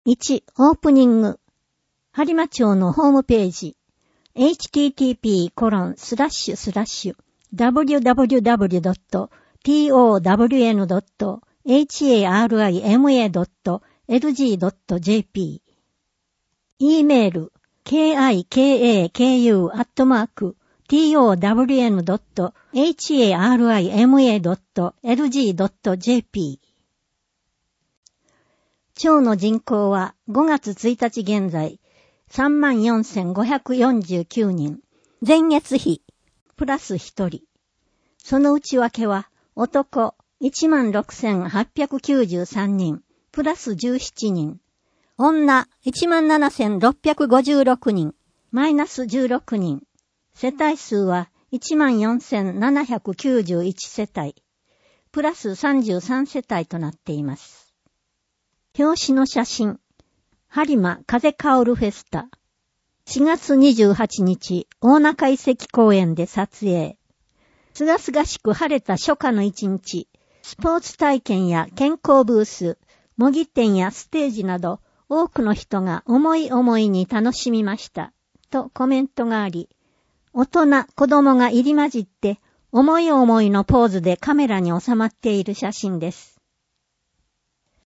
声の「広報はりま」はボランティアグループ「のぎく」のご協力により作成されています。